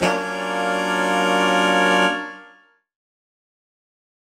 UC_HornSwellAlt_Dmin6maj7.wav